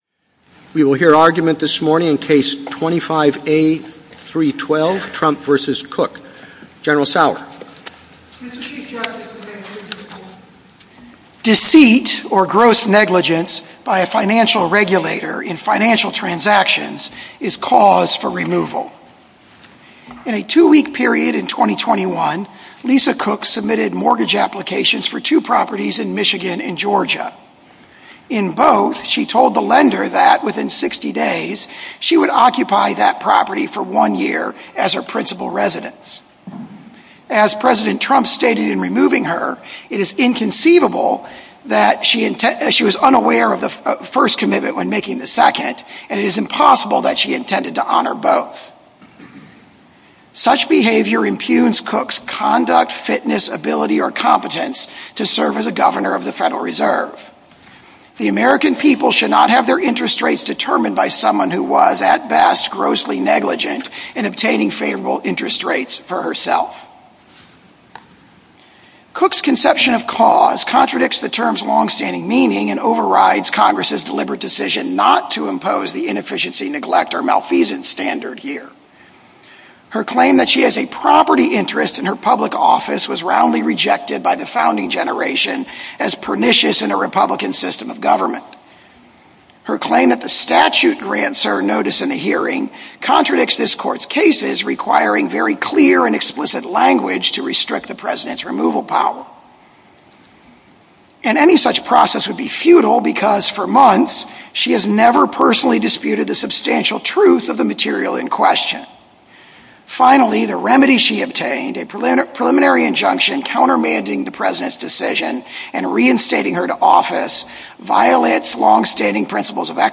Oral Arguments for the Supreme Court of the United States Trump, President of U.S. v. Cook Play episode January 21 1h 58m Bookmarks Episode Description Trump, President of U.S. v. Cook See all episodes